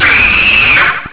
Halloween Audio Files for your enjoyment!
AGGGGHHHHH!!!
scream.au